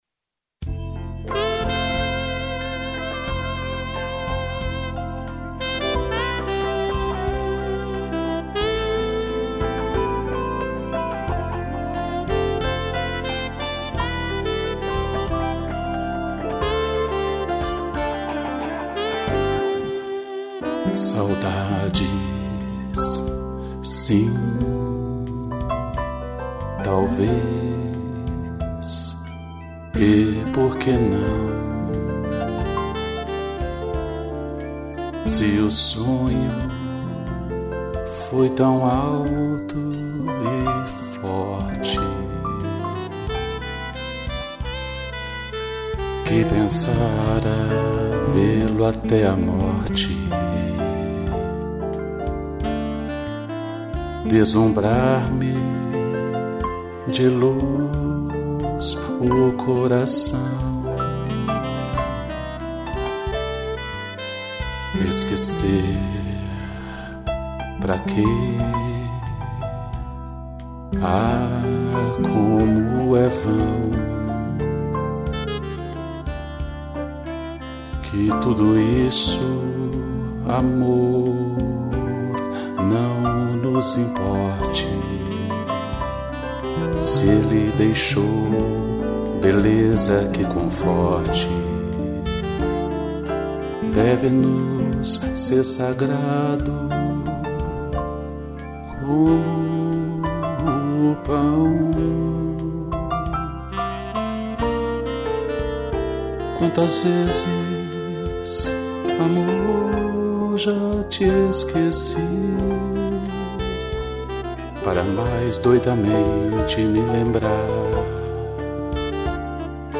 piano e sax